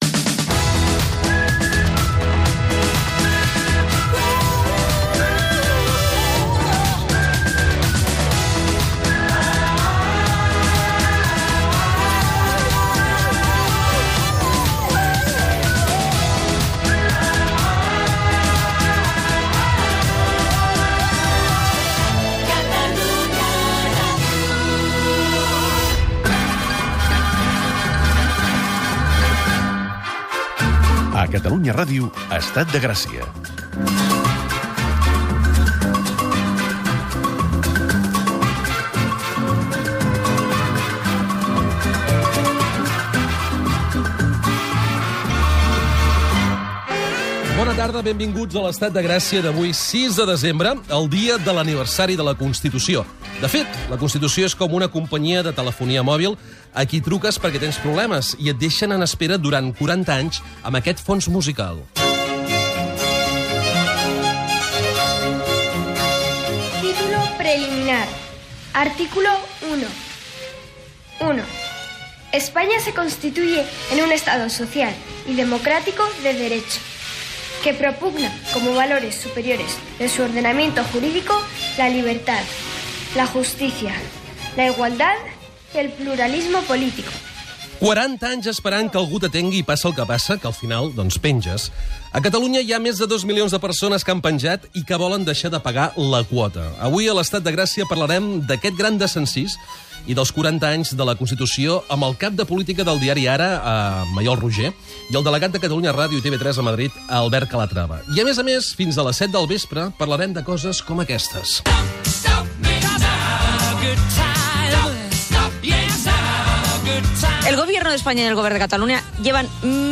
Indicatiu de la ràdio
Tertúlia d’actualitat